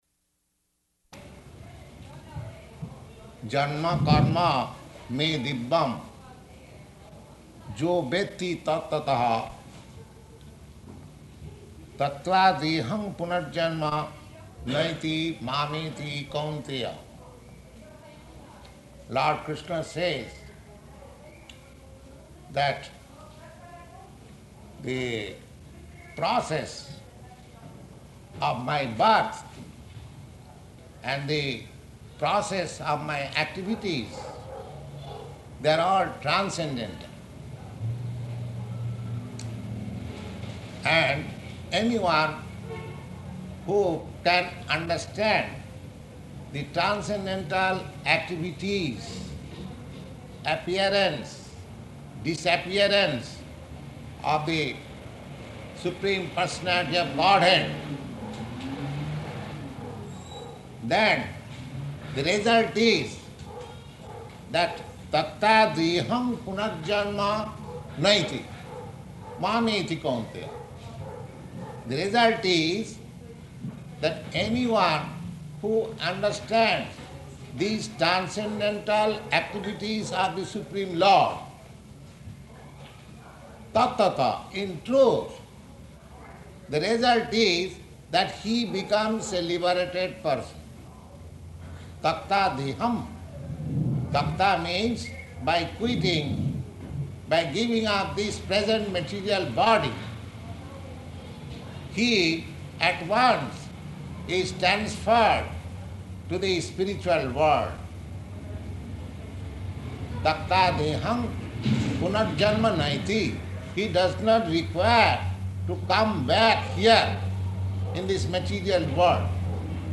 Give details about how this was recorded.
Location: New York